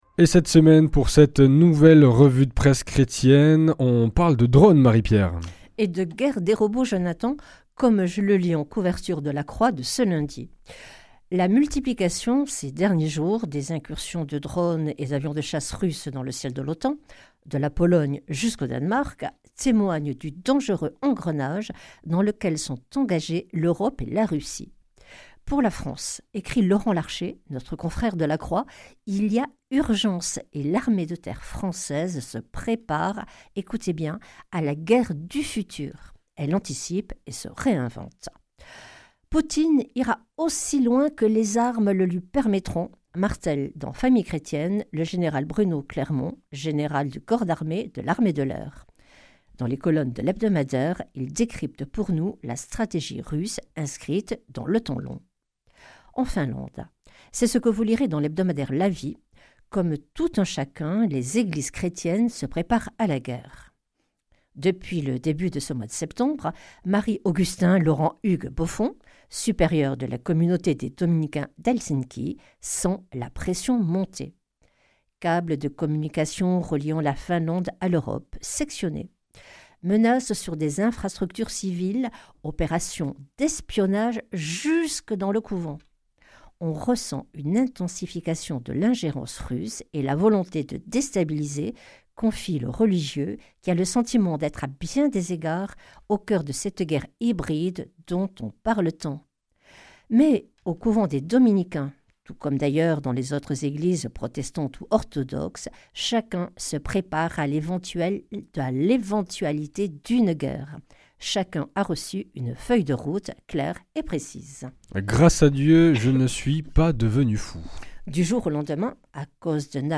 vendredi 3 octobre 2025 La revue de presse chrétienne Durée 5 min
Revue de presse
Journaliste